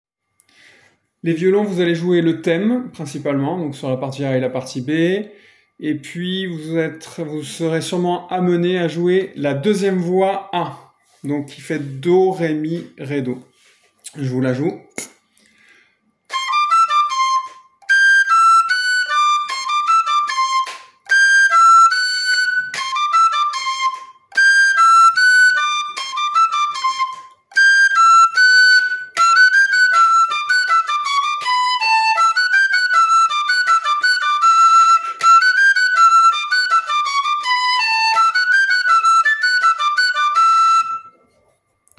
Flutes
les-boyaux-violons_1.mp3